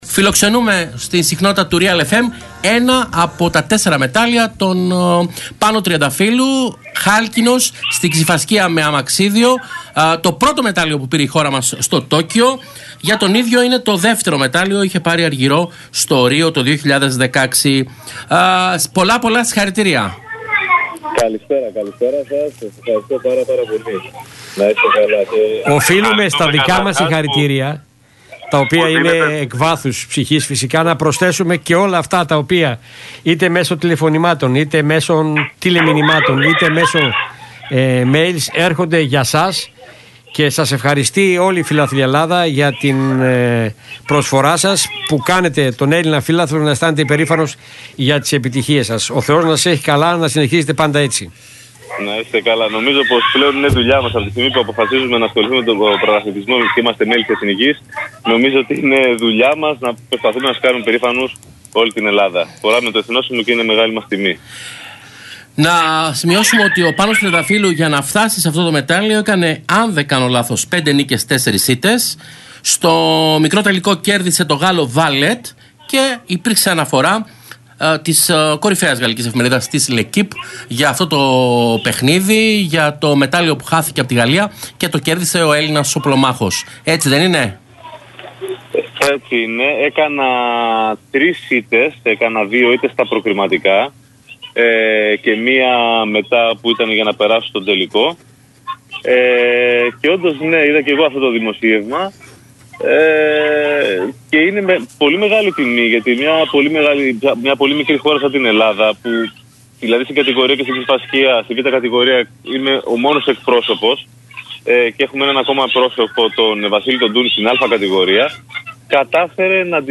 ανέφερε μιλώντας στον «RealFm 97,8» και στην εκπομπή Real Sports